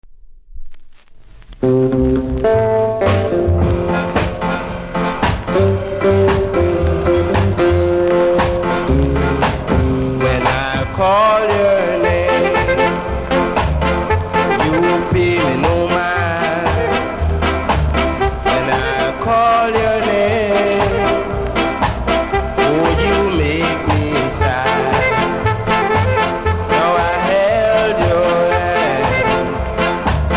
キズは多めですが音は良いので試聴で確認下さい。